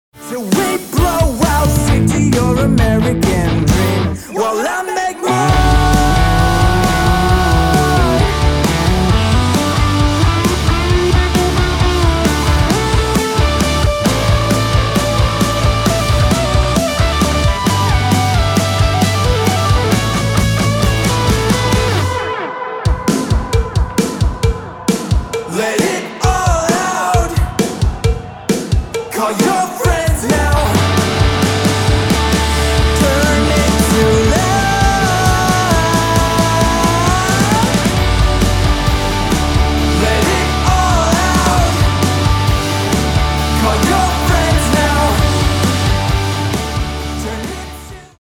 Punk Rock
Punk-Rock.mp3